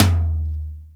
Index of /90_sSampleCDs/Roland L-CD701/TOM_Real Toms 1/TOM_Ac.Toms 1
TOM CROSS 0Y.wav